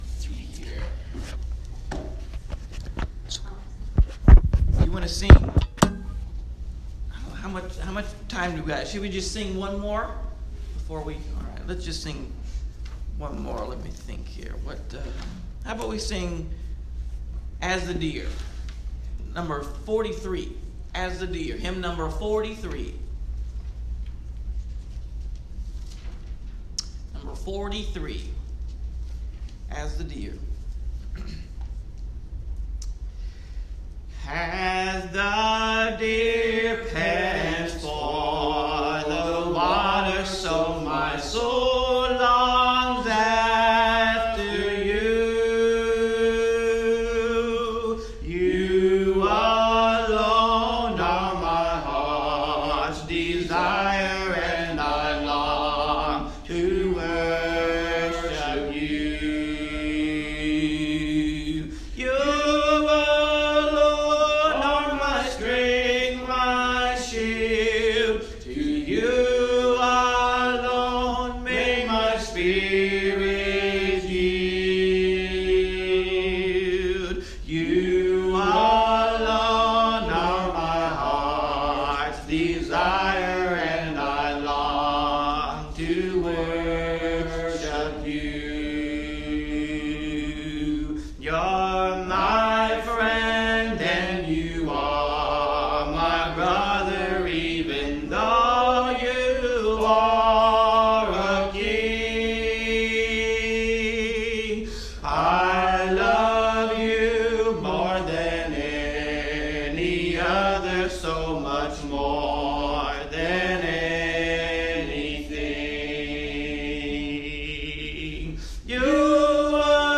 Audio Sermons & Lessons